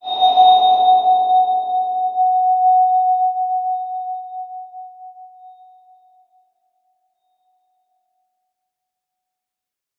X_BasicBells-F#3-mf.wav